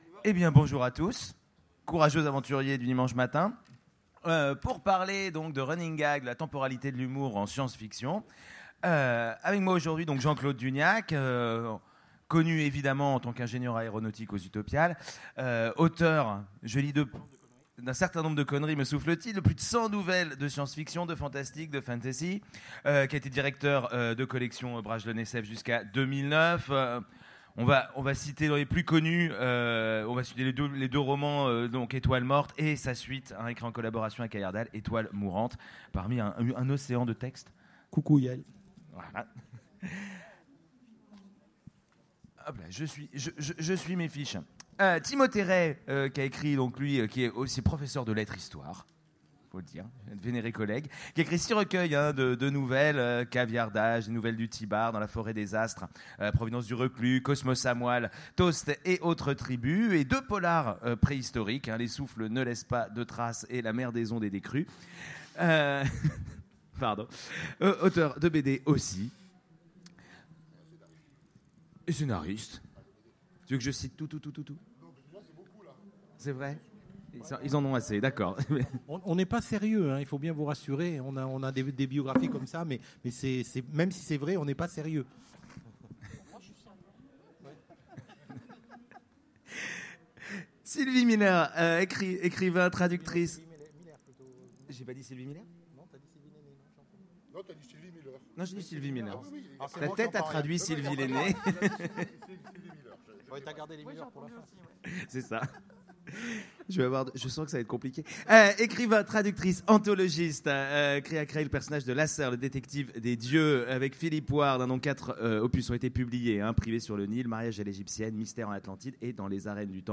Utopiales 2017 : Conférence Running gag, temporalité de l’humour en science-fiction